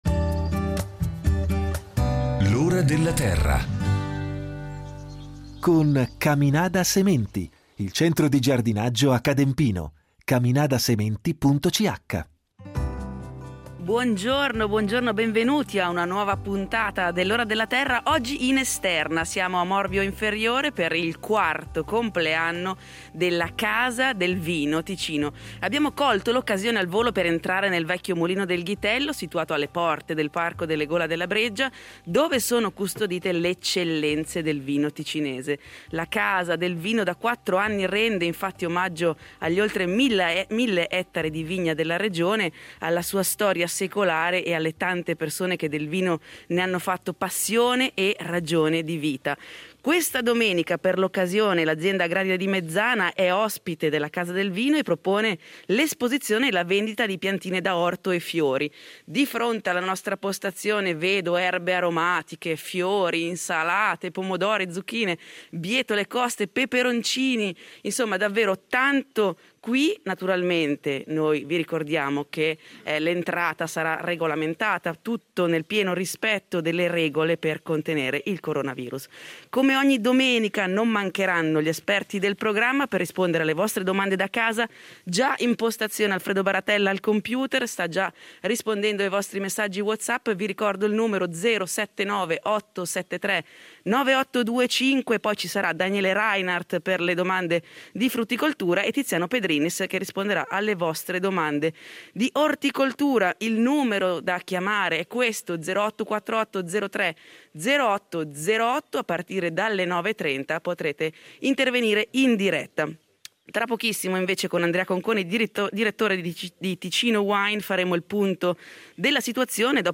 Per il quarto compleanno della Casa del Vino Ticino , l’Ora della Terra trasmette in diretta da Morbio Inferiore.